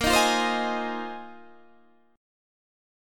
Bb7sus2sus4 chord